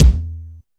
• Wet Bass Drum Single Hit E Key 185.wav
Royality free steel kick drum tuned to the E note. Loudest frequency: 569Hz
wet-bass-drum-single-hit-e-key-185-gMO.wav